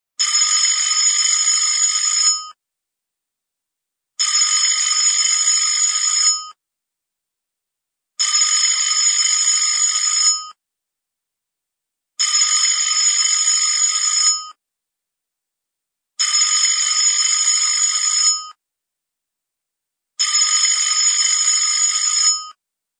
fast-alarm-clock_25569.mp3